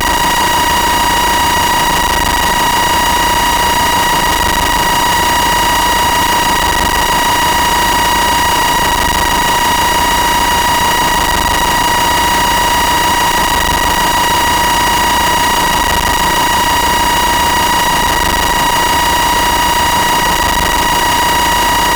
One Sharp Plugged in: Debug working, serin working but very slow